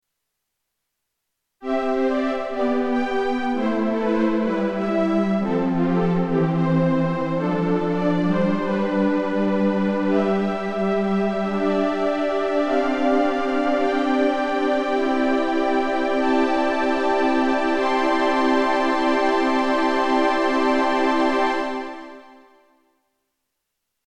Sauf mention explicite, les enregistrements sont faits sans aucun effet.
p.56 – Sledge : nappe de violons bien épaisse avec effet orchestral dense
SLEDGE nappe riche